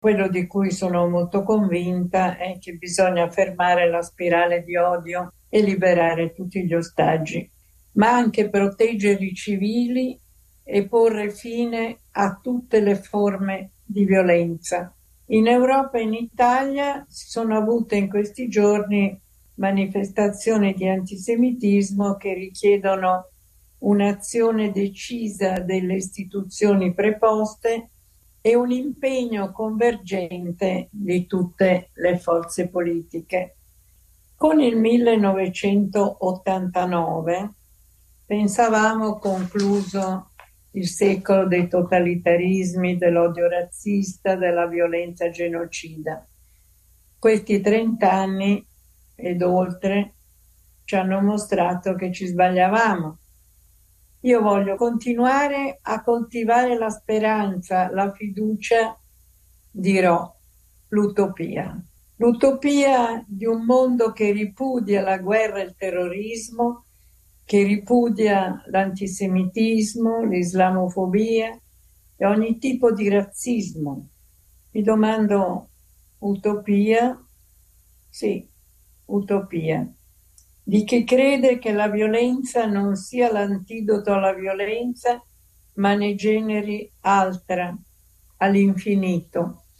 Oggi si è svolta in parlamento una seduta della commissione per il contrasto dei fenomeni di intolleranza, razzismo, antisemitismo e istigazione all’odio. In apertura dei lavori è intervenuta la presidente, Liliana Segre: